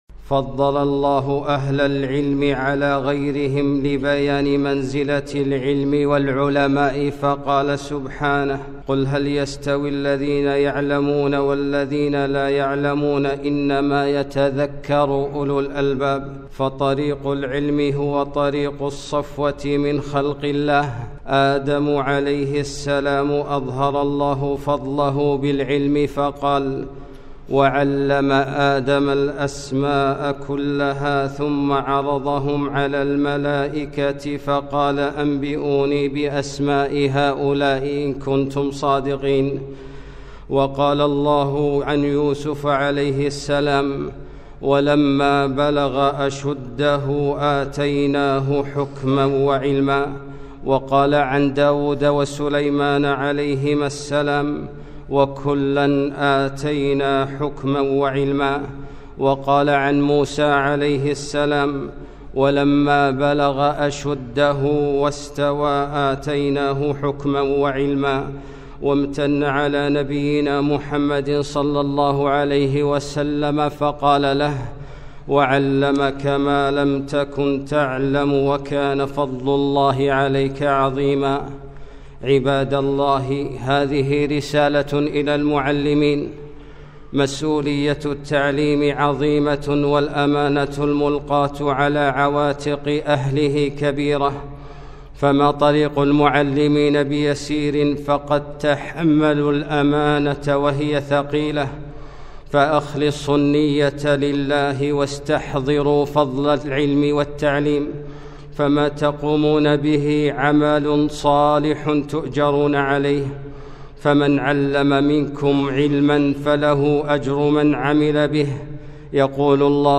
خطبة - رسالة إلى المعلمين